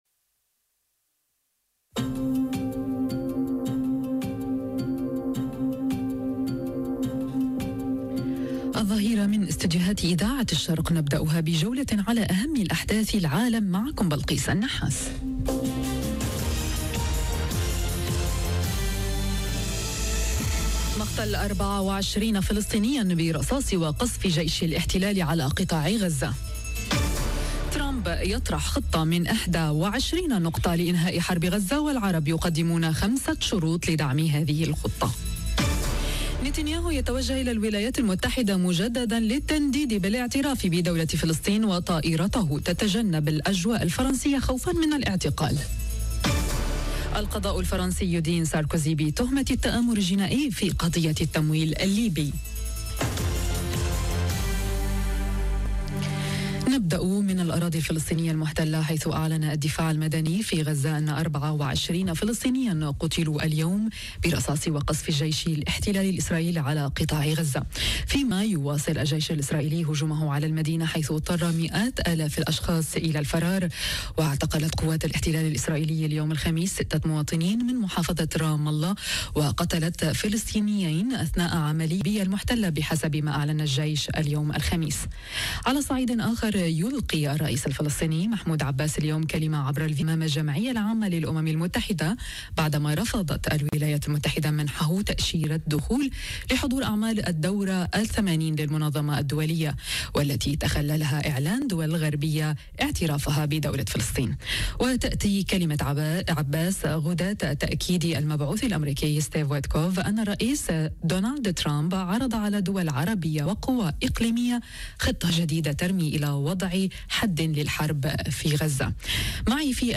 نشرة أخبار الظهيرة: مقتل 24 فلسطينيا برصاص وقصف جيش الاحتلال على قطاع غزة وترمب يطرح خطة من 21 نقطة لإنهاء حرب غزة.. والعرب يردون بـ5 شروط - Radio ORIENT، إذاعة الشرق من باريس